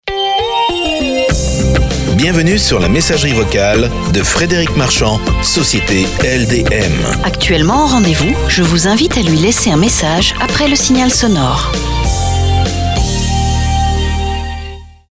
Message répondeur professionnel
ENREGISTREMENT DE QUATRE MESSAGES REPONDEUR PORTABLE LIBRES DE DROITS ET SEMI-PERSONNALISES – FR & EN
• 2ème étape: Nous enregistrons et mixons vos messages